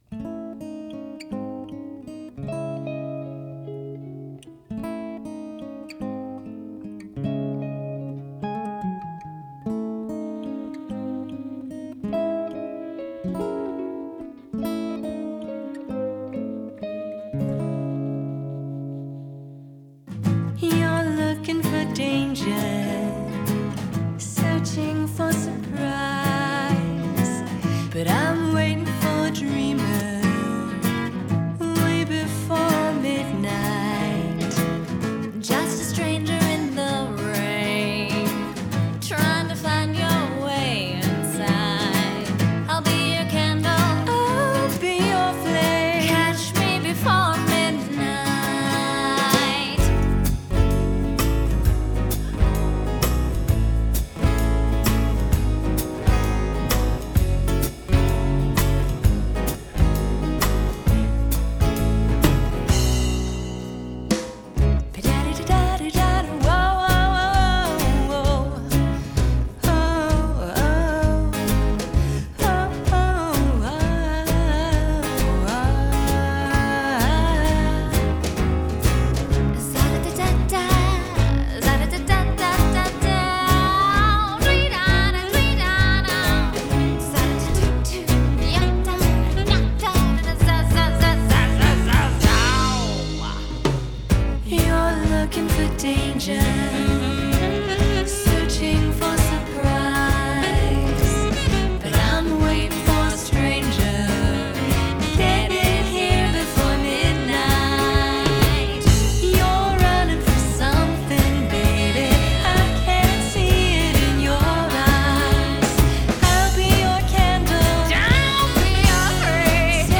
standup bass, piano, guitar, vocals
drums, percussion.
Genre: Jazz Vocals, Gipsy Swing